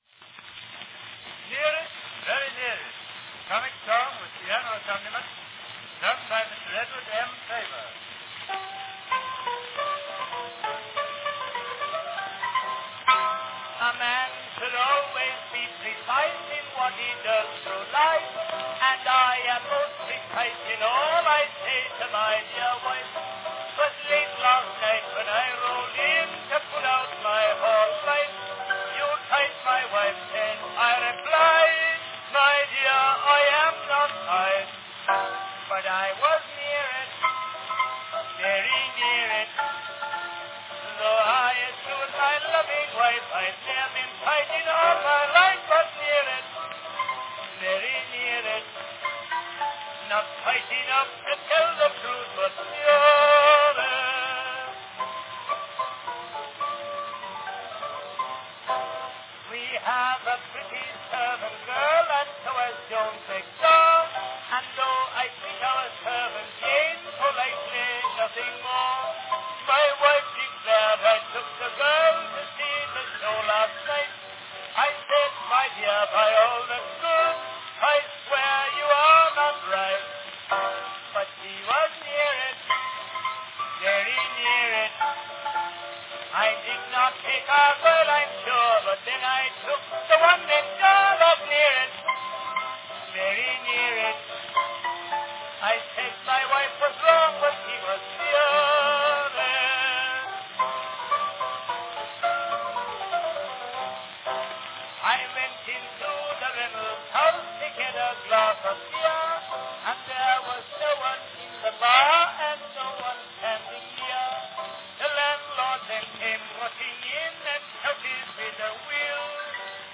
Category Comic song
This recording was probably produced by the Newark, New Jersey based United States Phonograph Company.